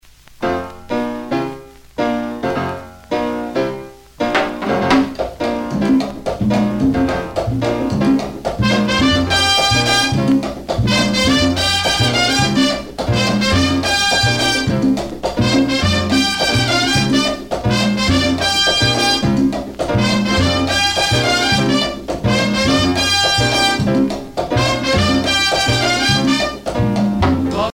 danse : pachanga (Cuba)
Pièce musicale éditée